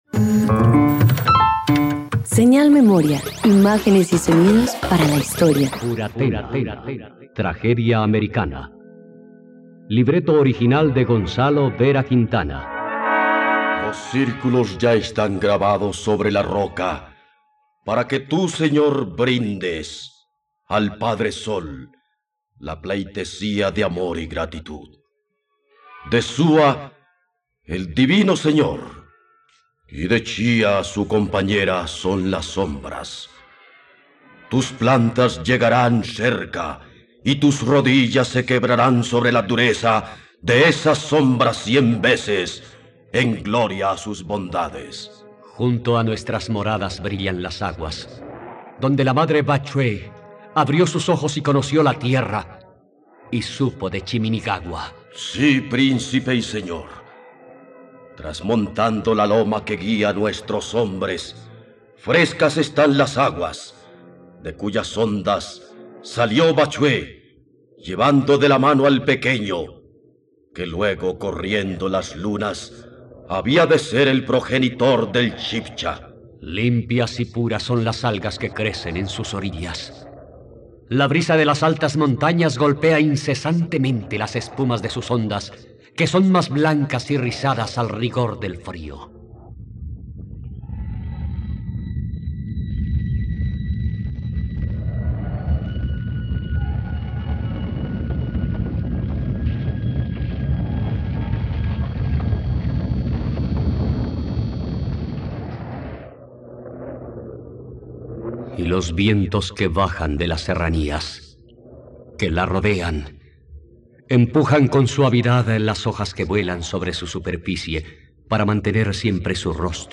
Furatena - Radioteatro dominical | RTVCPlay